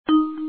alert.mp3